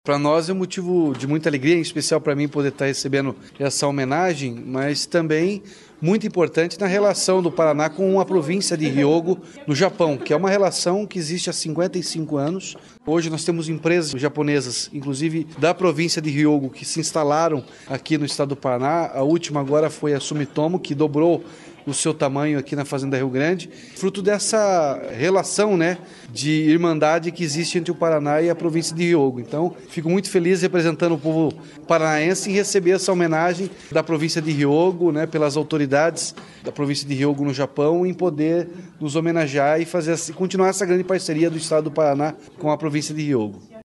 Sonora do governador Ratinho Junior sobre a honraria da província japonesa de Hyogo